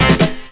DRUMFILL12-R.wav